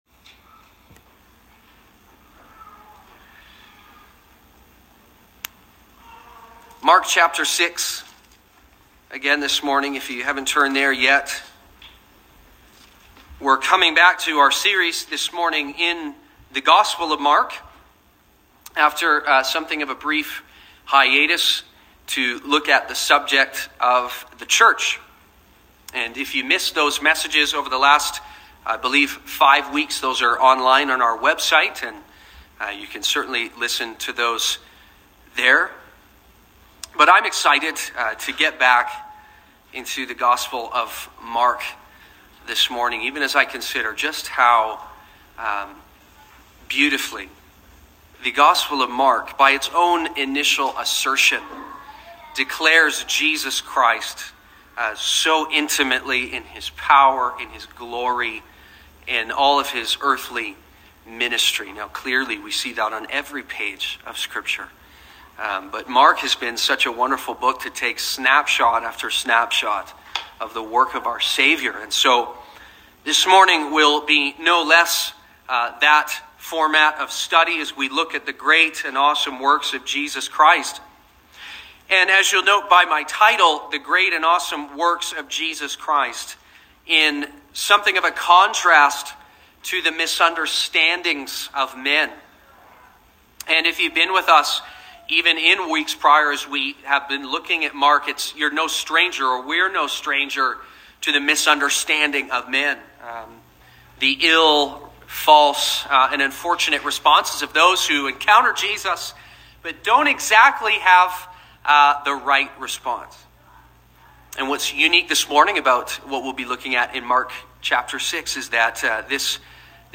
Sermons | Sonrise Community Baptist